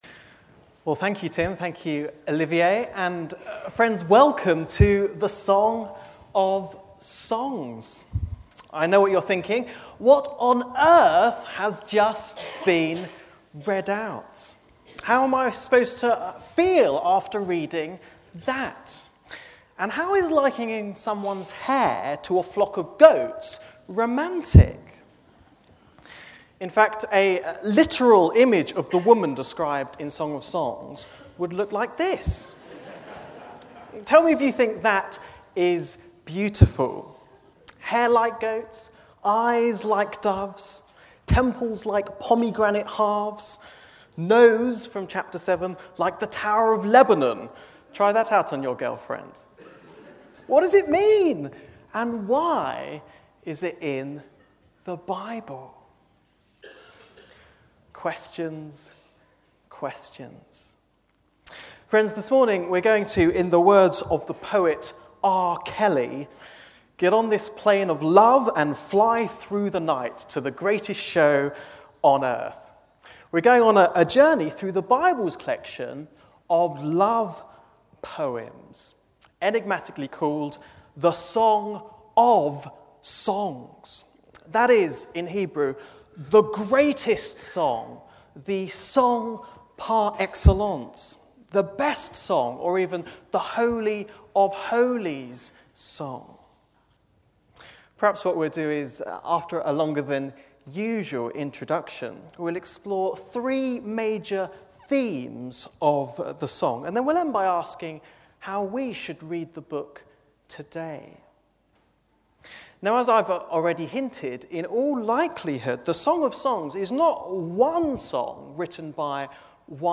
2017 Service type: Adult Bible Class Bible Text